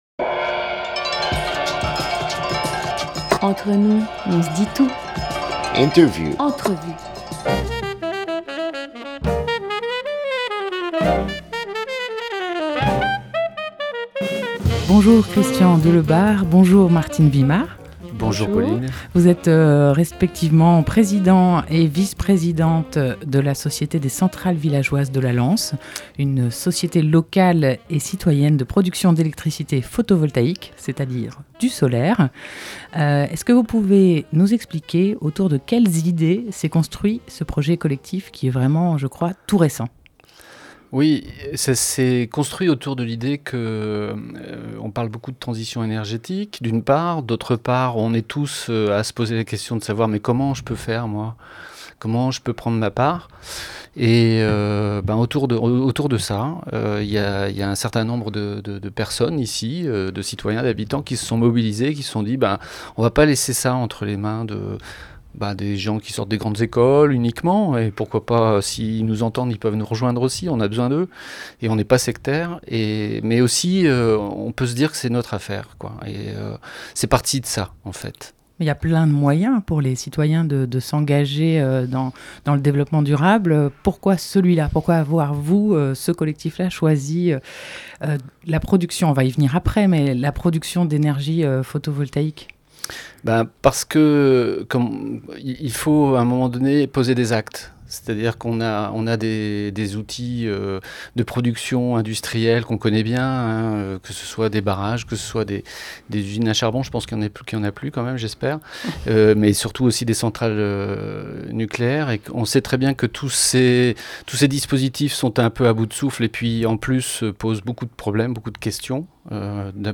19 octobre 2017 16:04 | Interview
ITW-Les-Centrales-Villageoises.mp3